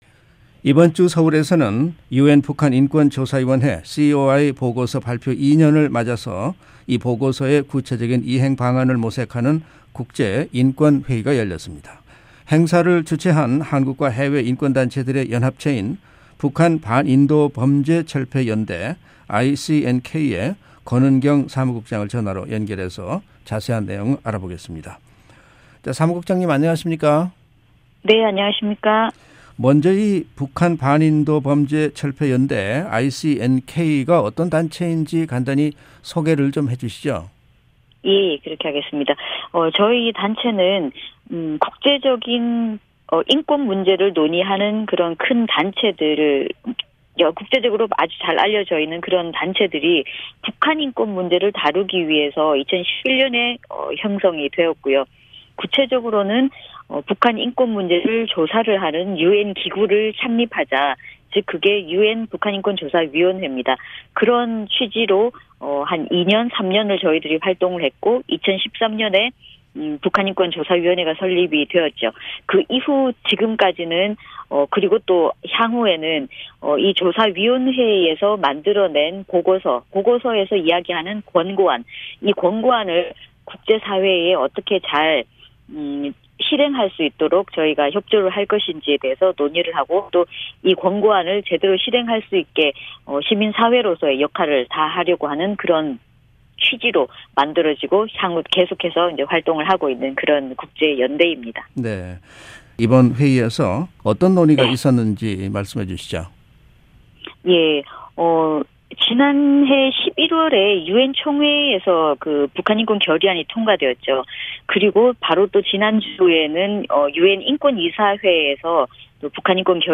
[인터뷰]
전화로 연결해 자세한 내용 알아보겠습니다.